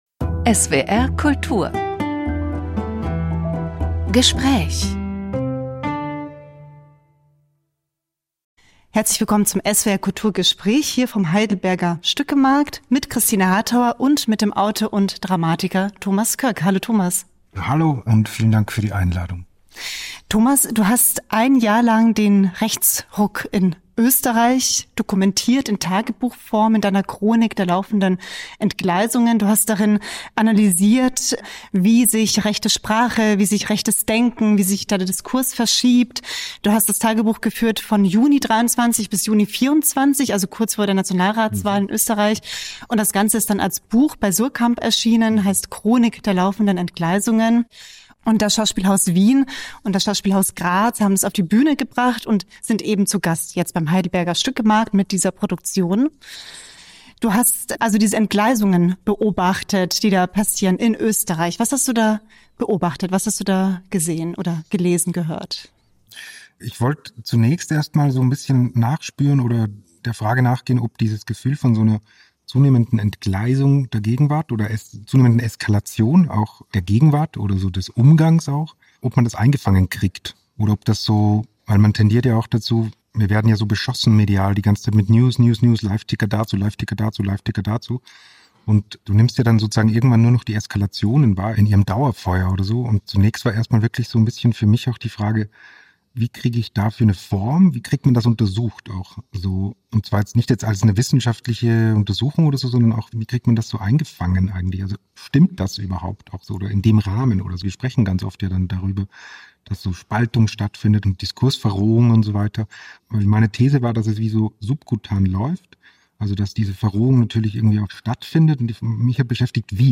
(Öffentliche Aufzeichnung vom 27. April 2025 im Theater Heidelberg)